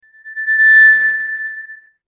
Microphone Feedback 03
Microphone_feedback_03.mp3